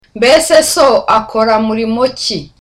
Dialogue
(Joyfully)